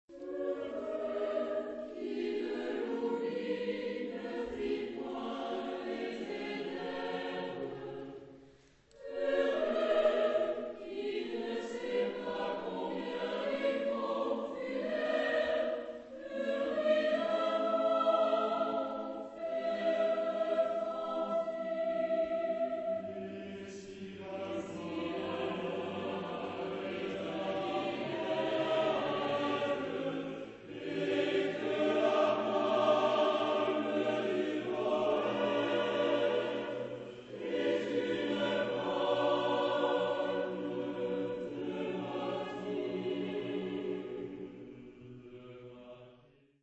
L'Obscurité, chœur à quatre voix mixtes a cappella (Victor Hugo)
Extrait sonore par le Choeur de Paris-Sorbonne : Loading � This work is licensed under a Creative Commons Attribution-NonCommercial-NoDerivs 3.0 Unported License .